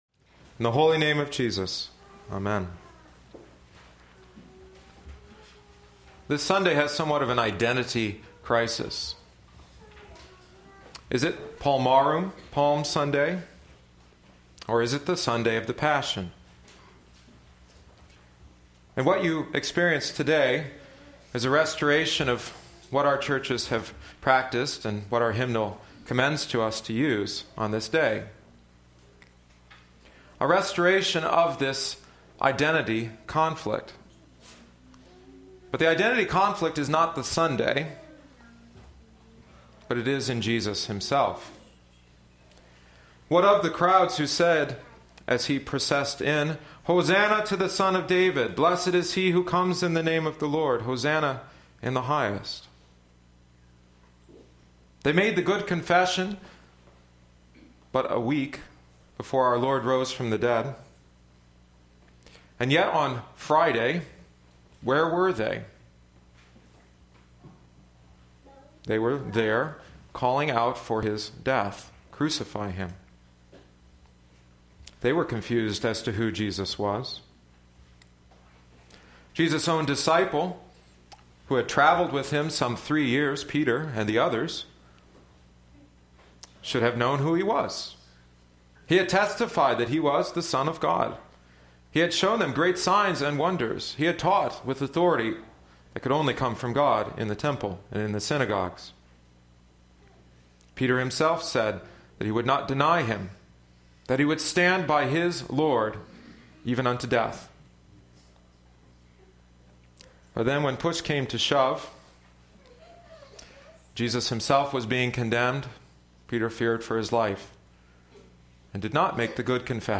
in Sermons |